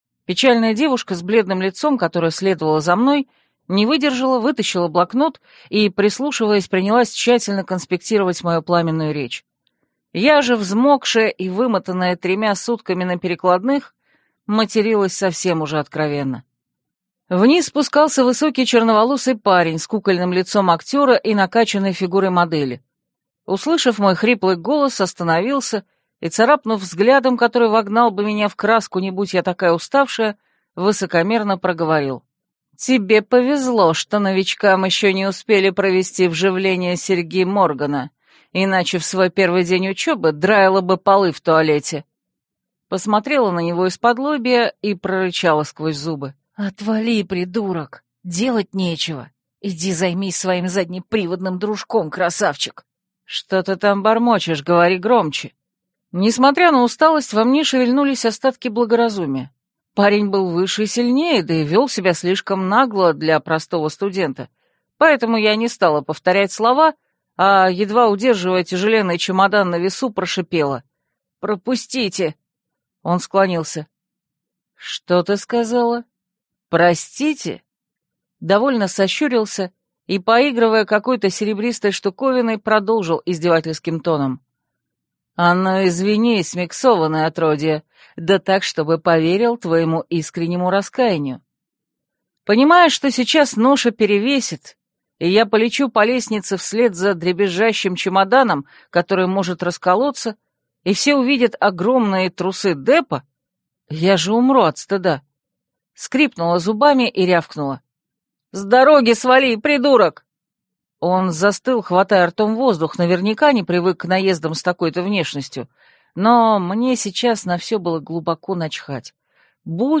Аудиокнига Академия оборотней: нестандартные. Книга 1 | Библиотека аудиокниг